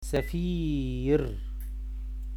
This lesson is for students who interests in learning Arabic Language for practicing politics or international press in Arabic . it contains 30 of the most common words in the field of The political field in Arabic. you can read and listen the pronunciation of each word .